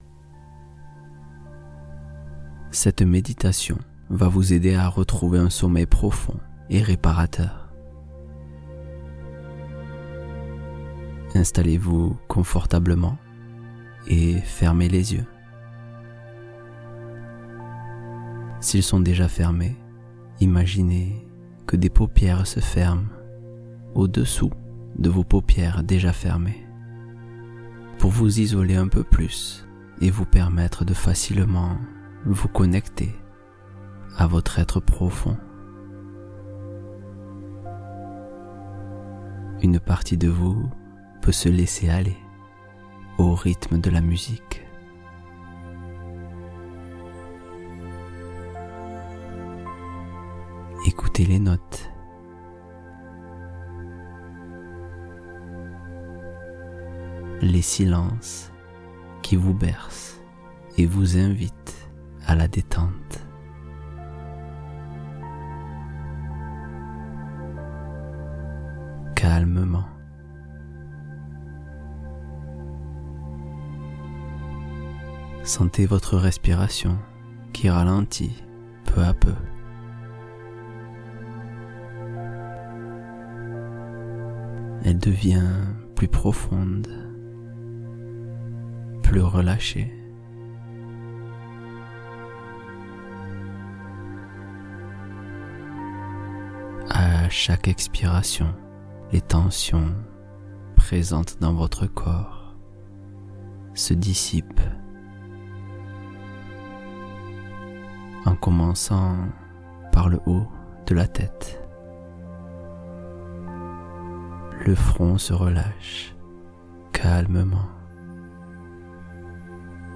La Méditation du Soir qui Garantit un Sommeil Profond (Testée par des Milliers)